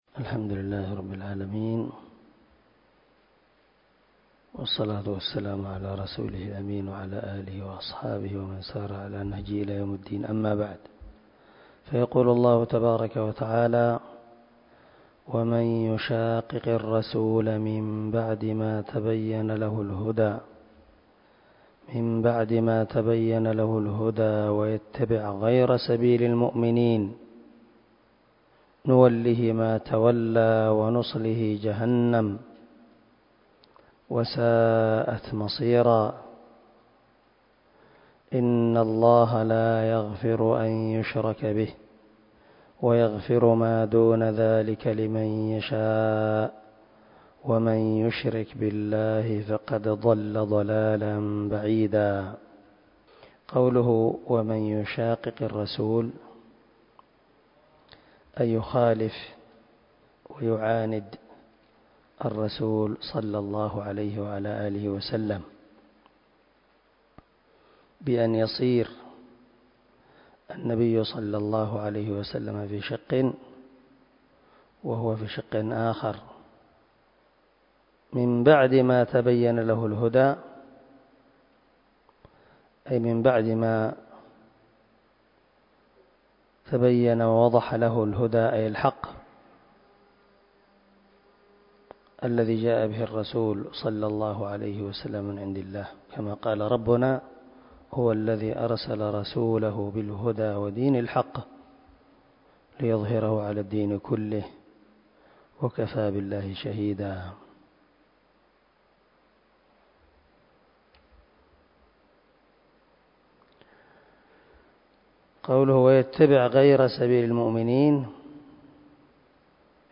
307الدرس 75 تفسير آية ( 115 – 116 )من سورة النساء من تفسير القران الكريم مع قراءة لتفسير السعدي
دار الحديث- المَحاوِلة- الصبيحة.